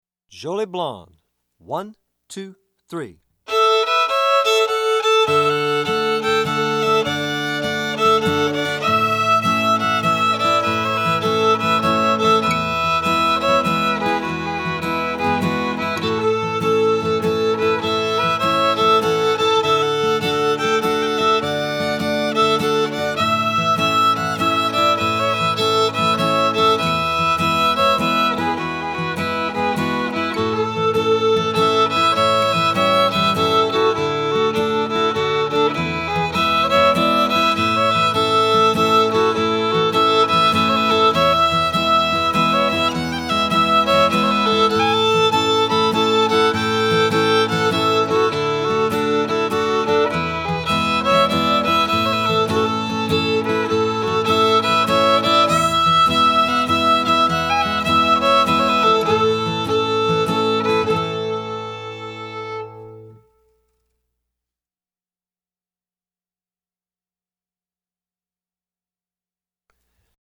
FIDDLE SOLO Fiddle Solo, Traditional, Cajun Waltz
DIGITAL SHEET MUSIC - FIDDLE SOLO